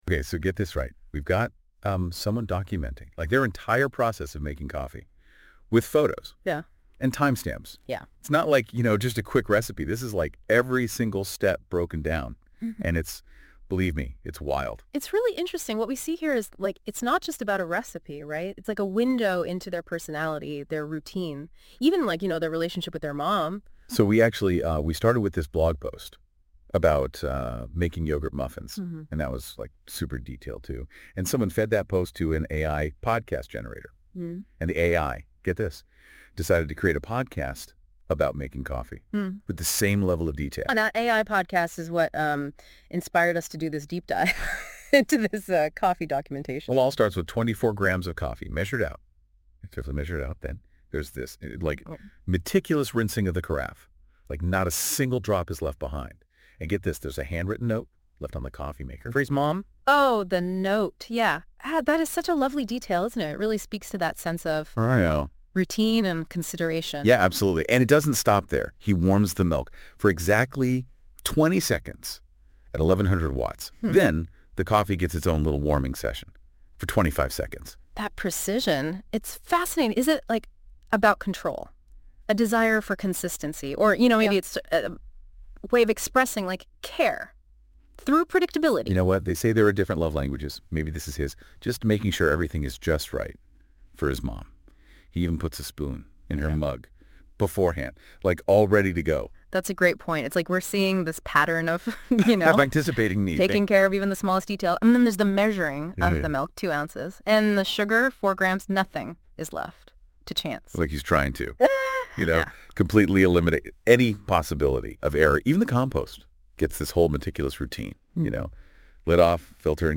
As such, my brother and I tried to make podcasts about the post using Google’s NotebookLM Podcast Generator.